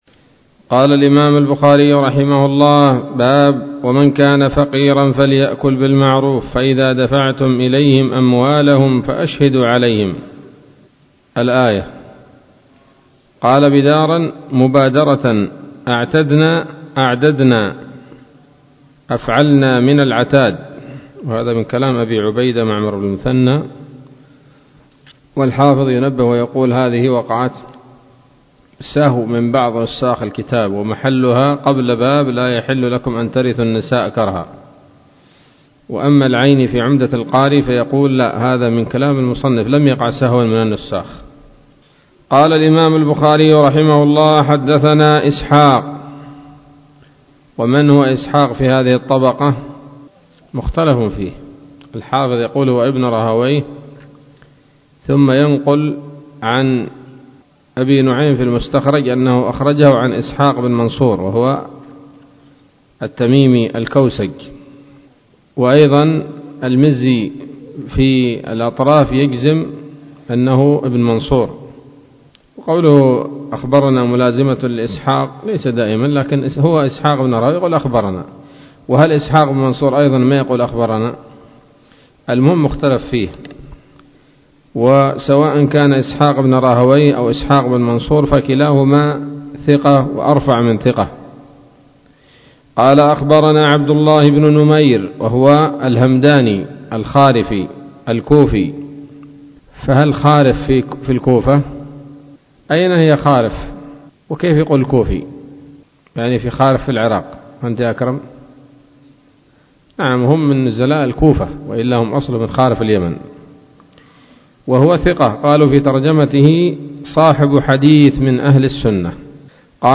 الدرس الثالث والستون من كتاب التفسير من صحيح الإمام البخاري